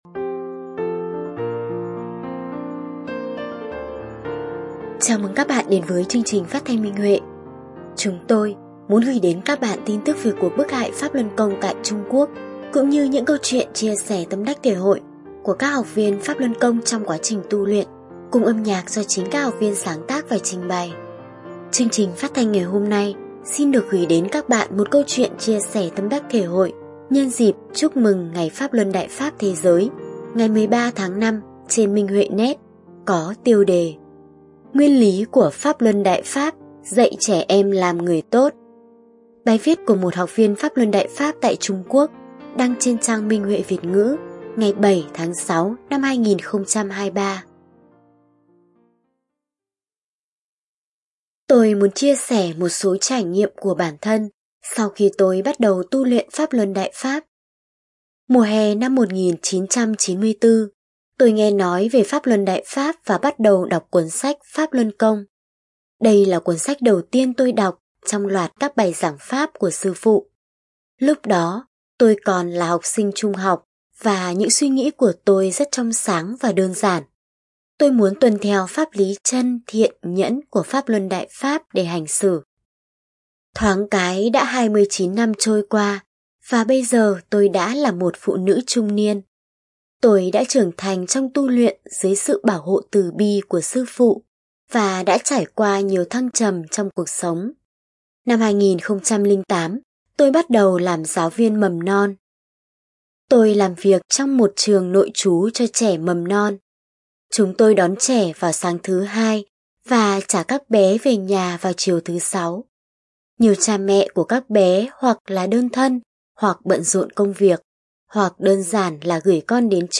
Chương trình phát thanh số 9: Bài viết chia sẻ tâm đắc thể hội nhân dịp Chúc mừng Ngày Pháp Luân Đại Pháp Thế giới trên Minh Huệ Net có tiêu đề Nguyên lý của Pháp Luân Đại Pháp dạy trẻ em làm người tốt, bài viết của đệ tử Đại Pháp tại Trung Quốc Đại Lục.